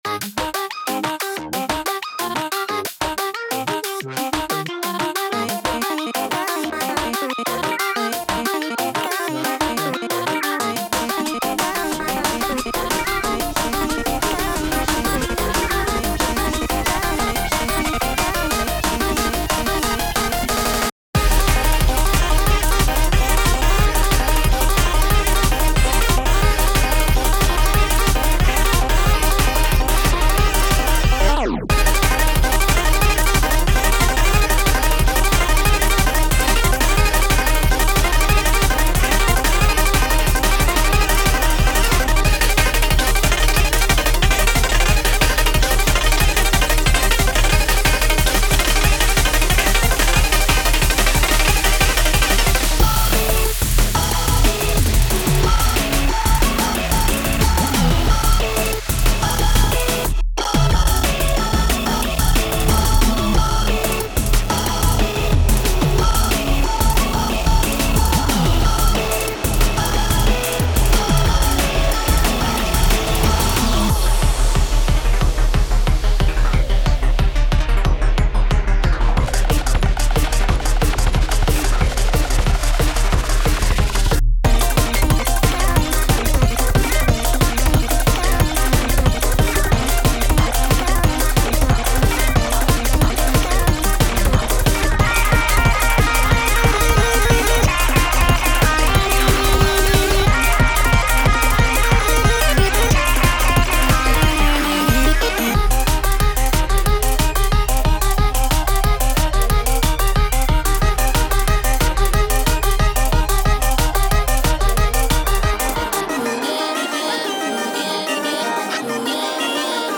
タグ: EDM Kawaii 明るい/楽しい コメント: バレンタインに向けて作ったkawaii系楽曲。